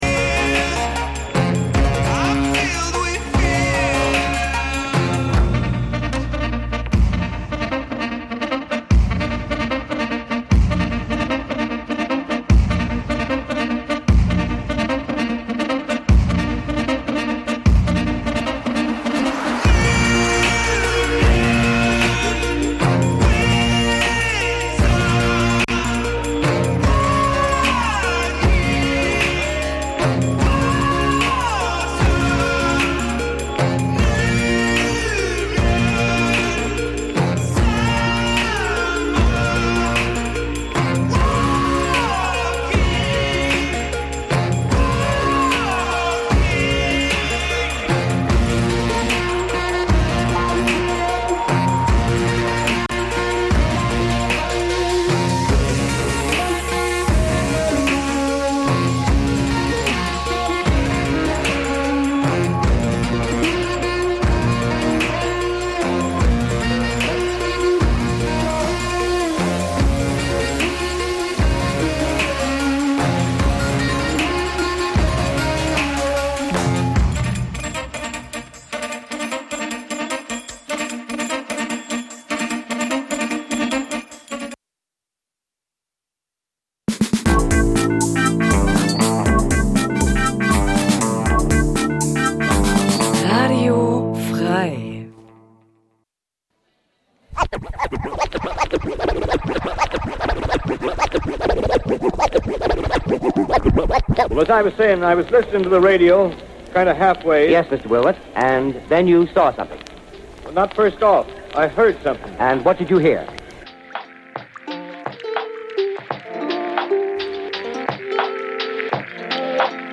Wenn der Groove Deine Seele streichelt, die Drums ungezogen ins Ohr fl�stern, w�hrend Loops und Schleifen in entspannten Kreisen schweifen und dabei mehr gelacht als gedacht wird, sind Deine Ohren bei Radio Bounce - Gurgelnd knusprige Wellen aus der Hammerschmiede f�r leidenschafltiche Sch�ngeister, pudelnackt!